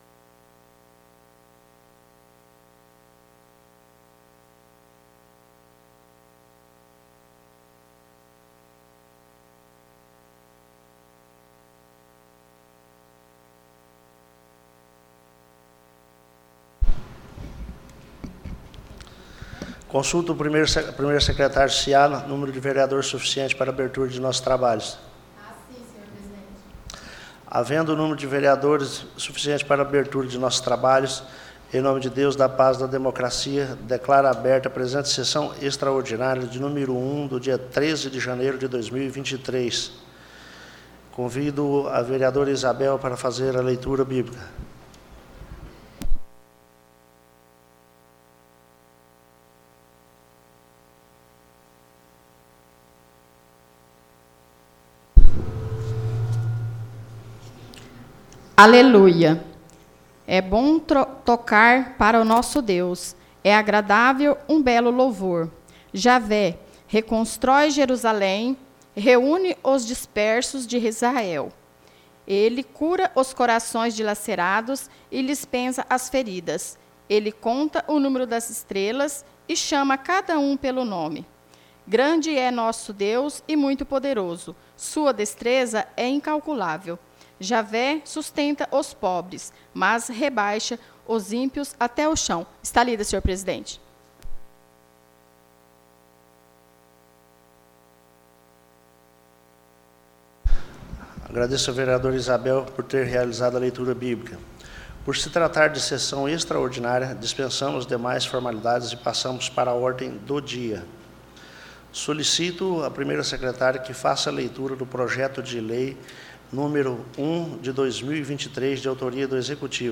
Áudio Sessão Extraordinária nº 001/23